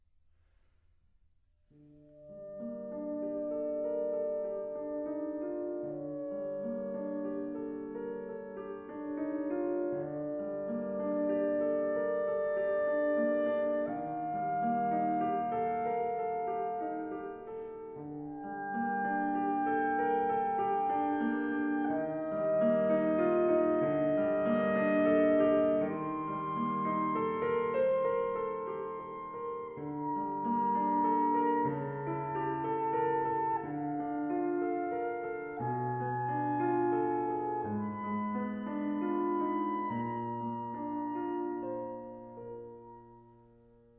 Neue Musik
Ensemblemusik
Duo
Klarinette (1), Klavier (1)